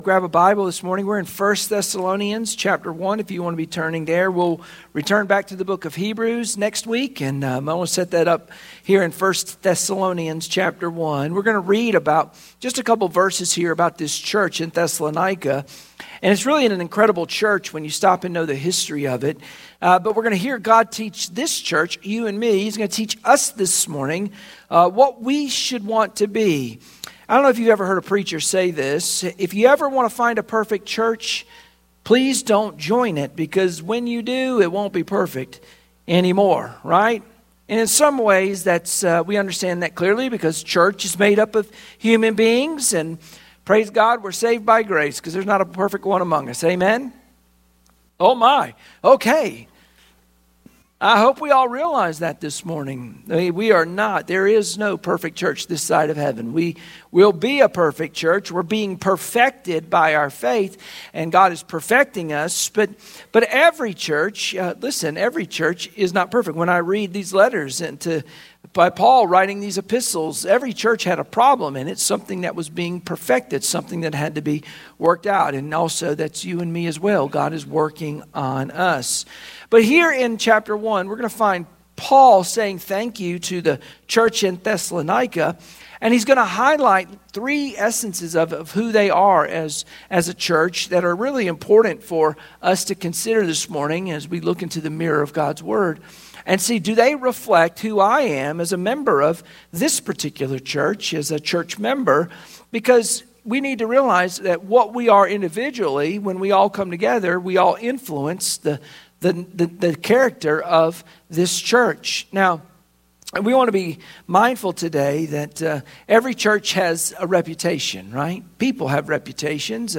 Sunday Morning Worship Passage: 1 Thessalonians 1:1-4 Service Type